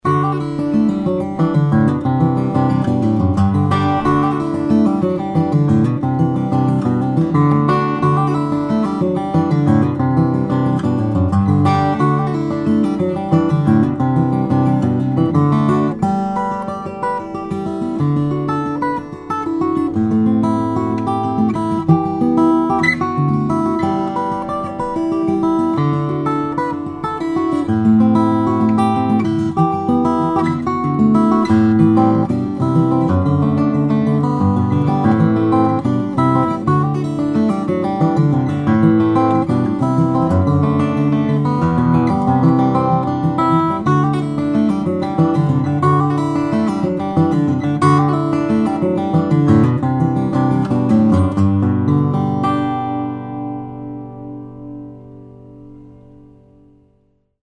The voice is huge and can morph… Read more »